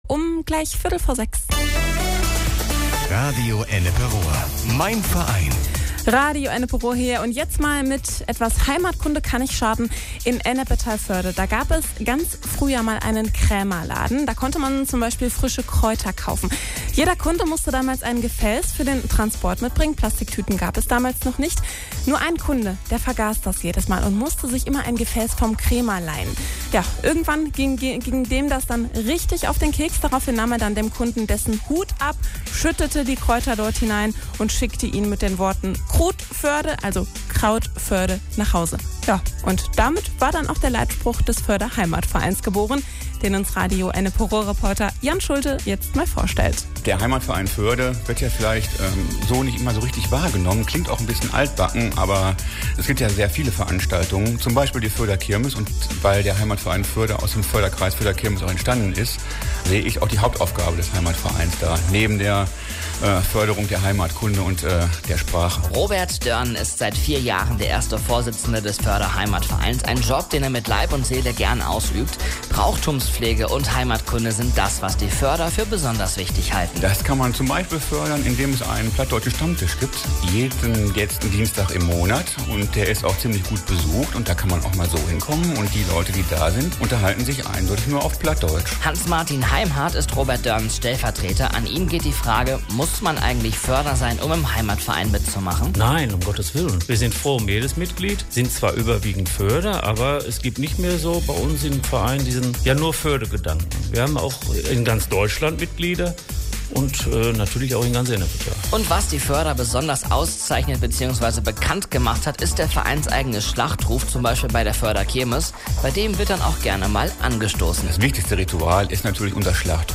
Rundfunkbeitrag bei Radio Ennepe-Ruhr
nachtwAm vergangenen Donnerstag ging ein Radiobeitrag über den Äther, der bei Radio Ennepe-Ruhr zur Reihe „Mein Verein“ produziert wurde.